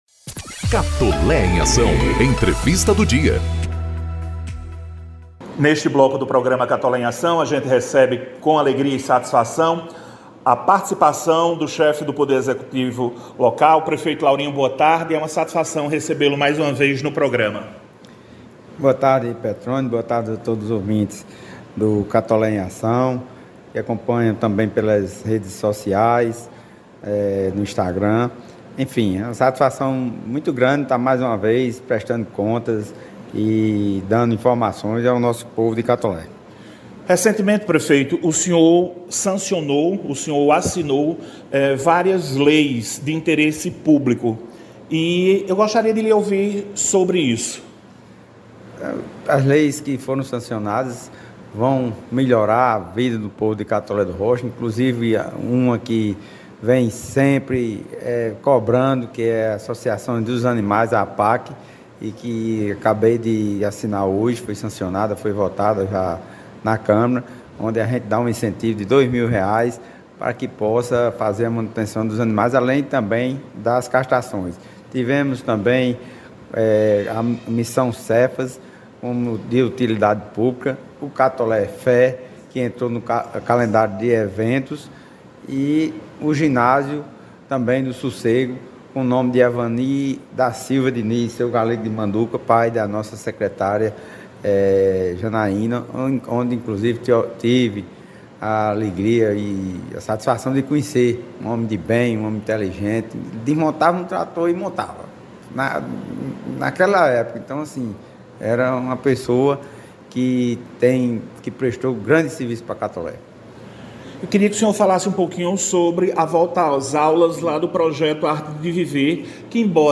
Prefeito Laurinho Maia destaca avanços e projetos da gestão em entrevista no Programa Catolé em Ação. Ouça a entrevista! - Folha Paraibana
Entrevista-Pref.-Laurinho-Maia-online-audio-converter.com_.ogg